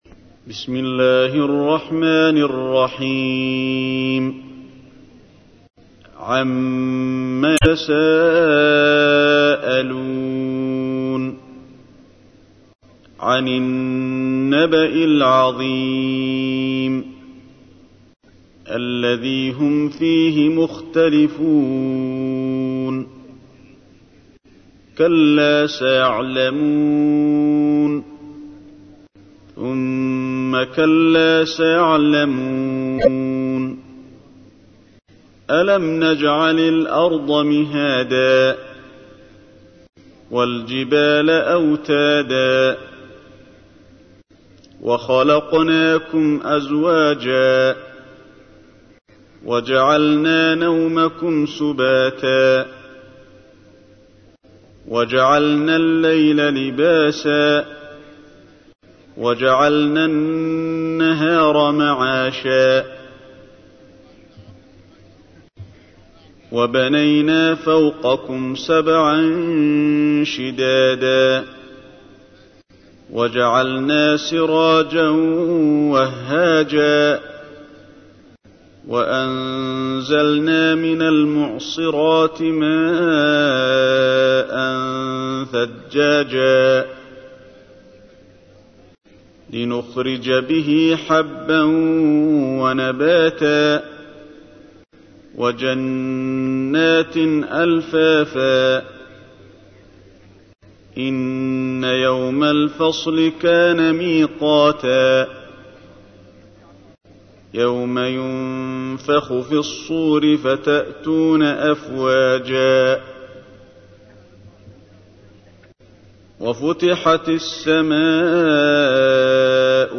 تحميل : 78. سورة النبأ / القارئ علي الحذيفي / القرآن الكريم / موقع يا حسين